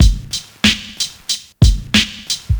• 93 Bpm '90s Hip-Hop Breakbeat G Key.wav
Free drum beat - kick tuned to the G note. Loudest frequency: 1880Hz
93-bpm-90s-hip-hop-breakbeat-g-key-inR.wav